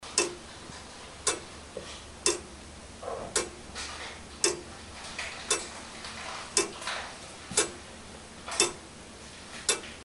3600 A/H
Une alternance par seconde. Les horloges « Comptoise » battent aussi la seconde. C’est le fameuse Tic-Tac que tout le monde connais.
3600-Comptoise-SOUND.mp3